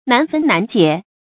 难分难解 nán fēn nán jiě
难分难解发音